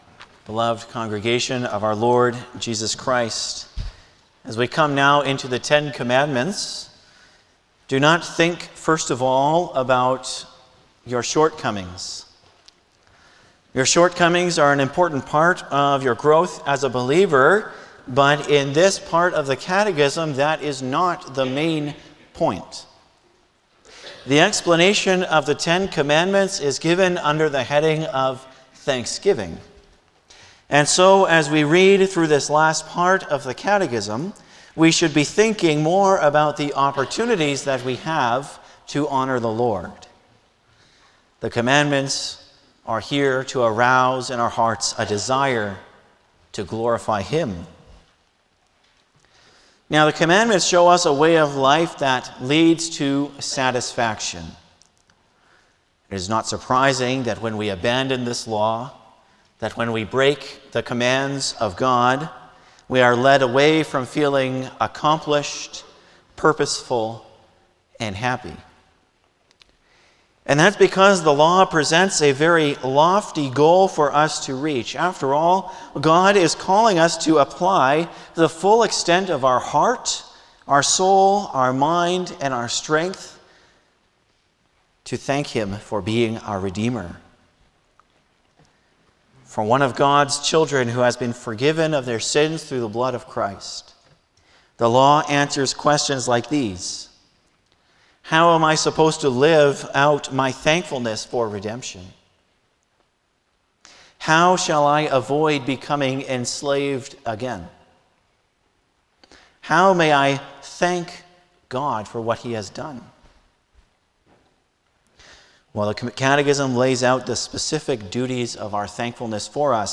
Passage: Lord’s Day 34 Service Type: Sunday afternoon
07-Sermon.mp3